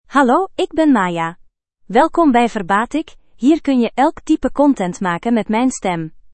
Maya — Female Dutch (Belgium) AI Voice | TTS, Voice Cloning & Video | Verbatik AI
Maya is a female AI voice for Dutch (Belgium).
Voice sample
Listen to Maya's female Dutch voice.
Maya delivers clear pronunciation with authentic Belgium Dutch intonation, making your content sound professionally produced.